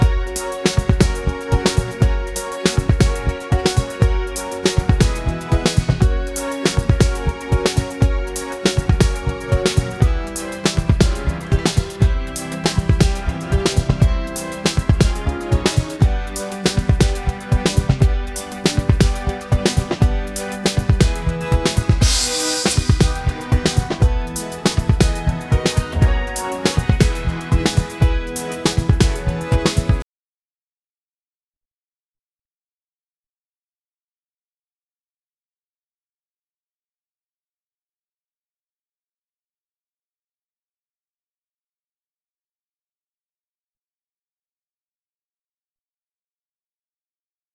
music-generation
sao_B_Major_jazz_2.wav